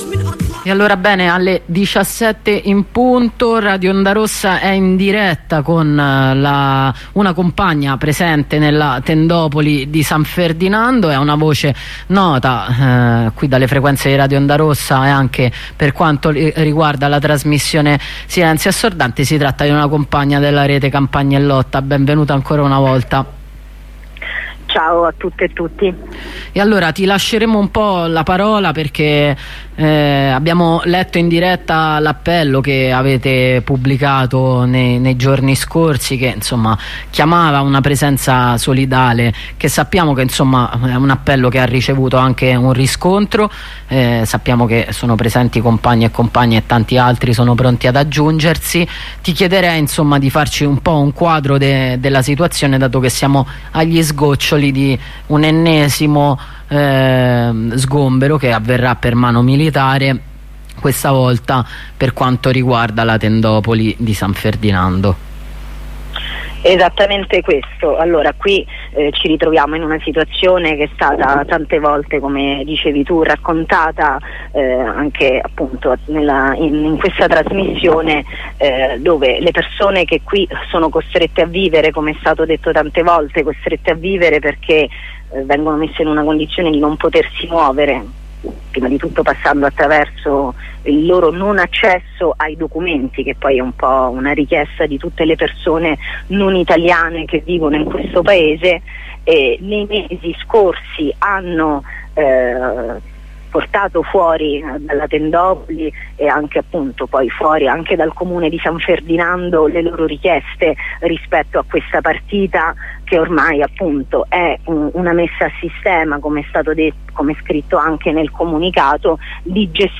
Con una compagna della Rete Campagne in Lotta, presente a San Ferdinando con altri solidali, facciamo il quadro della situazione nel clima pesante che precede lo sgombero della tendopoli.